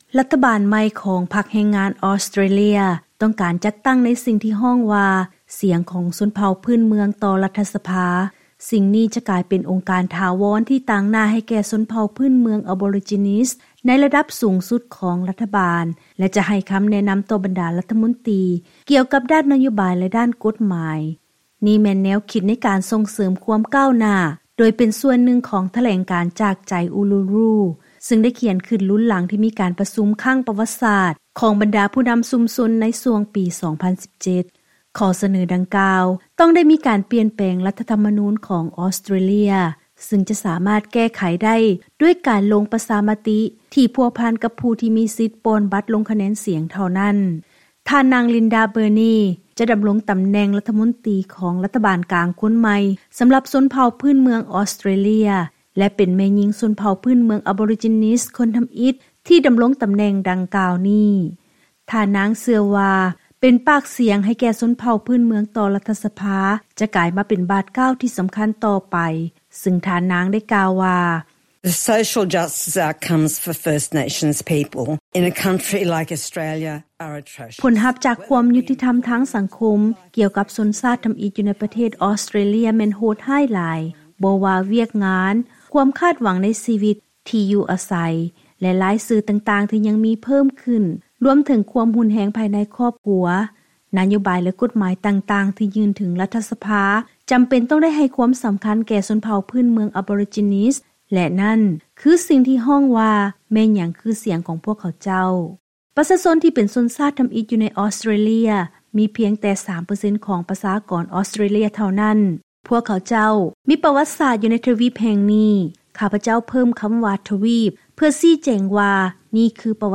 ລາຍງານກ່ຽວກັບ ນະໂຍບາຍຂອງລັດຖະບານໃໝ່ອອສເຕຣເລຍ ກ່ຽວກັບສິດທິຊົນເຜົ່າພື້ນເມືອງ